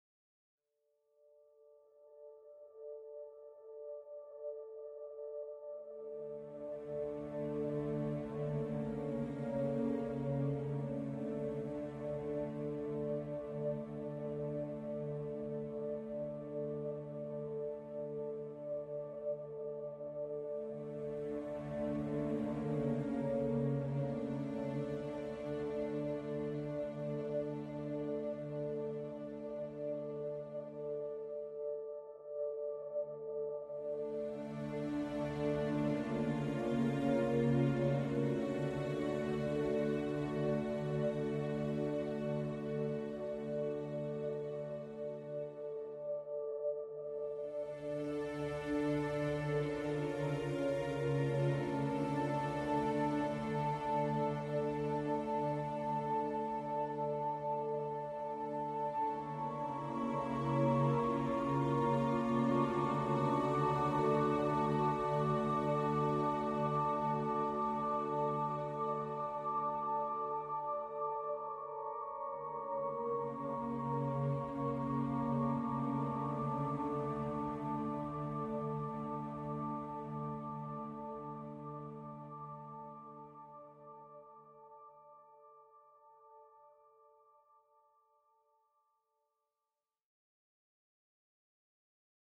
Only Pads